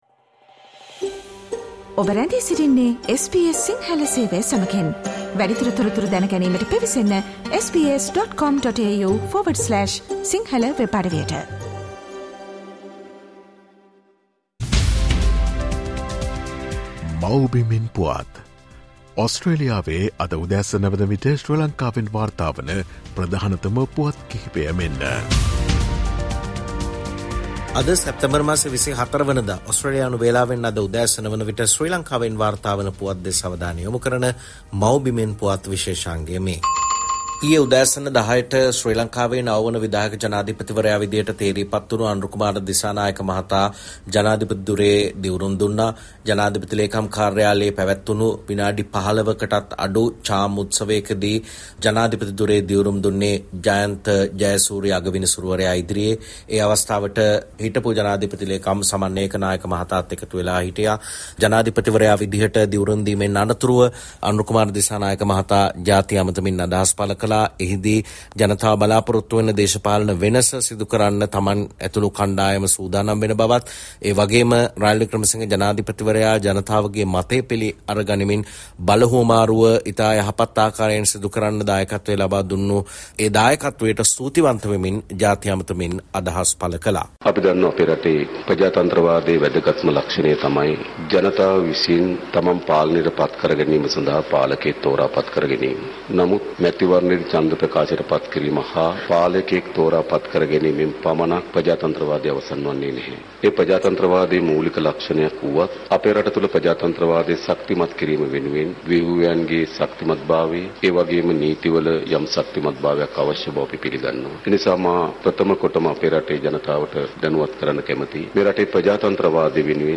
Here are the most prominent News Highlights of Sri Lanka.